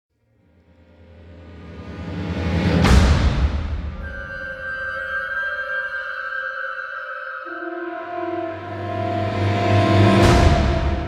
Звуки кошмара
Атмосфера тревоги и настороженности в кино